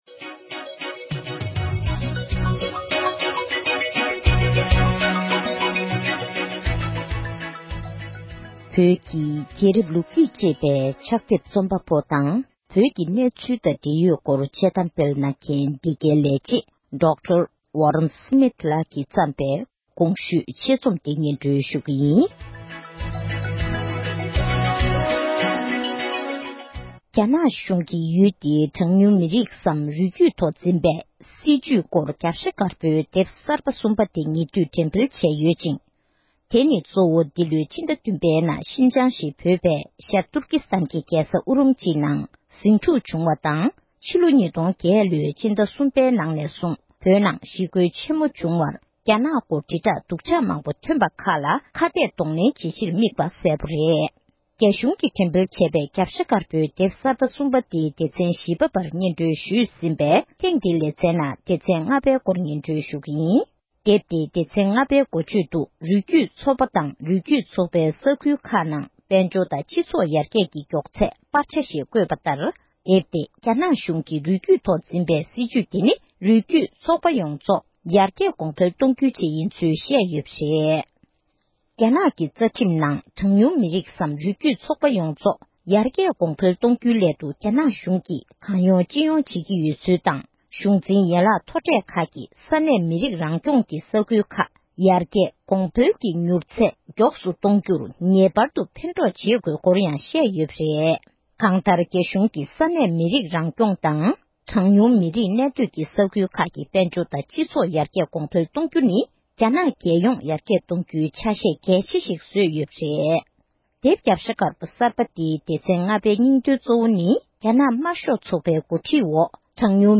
སྒྲ་ལྡན་གསར་འགྱུར།
ཕབ་བསྒྱུར་དང་སྙན་སྒྲོན་ཞུས་པར་གསན་རོགས༎